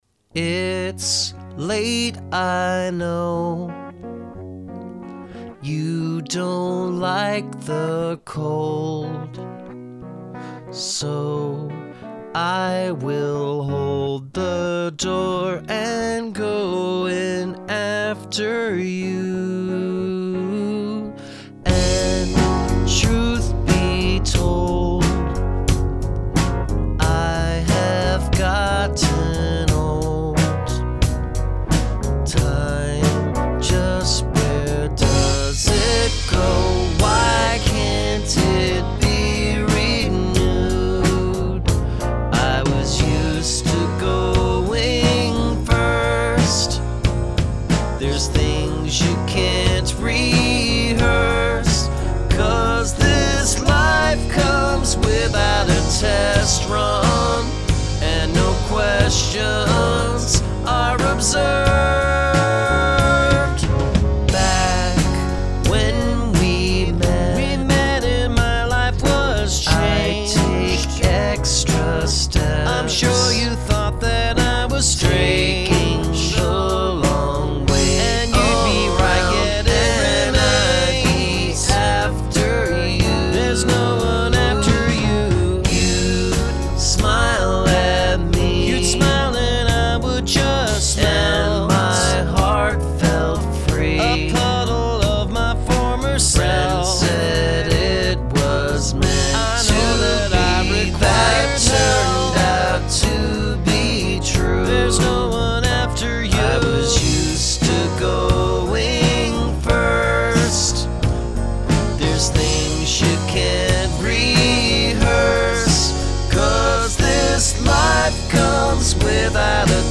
Counter Melody
Lovely melodies, lovely singing!
With that in mind, I think the drums are too much.